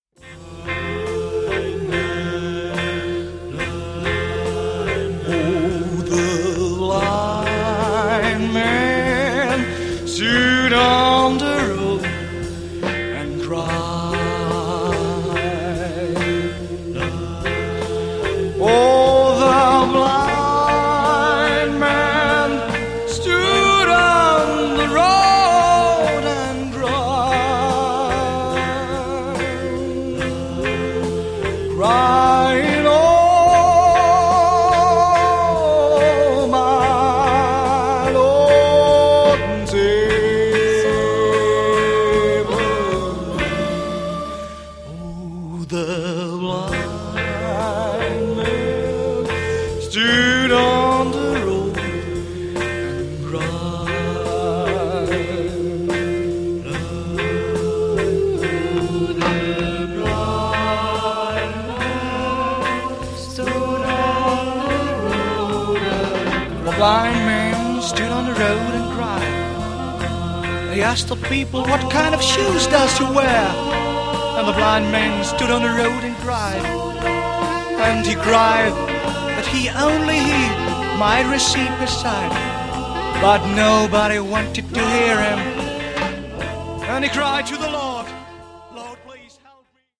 Zwei ausdrucksvolle englischsprachige Gospel-Songs
Gospel